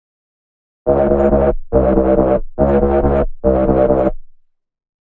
描述：140 bpm的dubstep低音。由自制的贝斯样本制成（标语有更多信息）。
Tag: 低音 回响贝斯 循环 摆动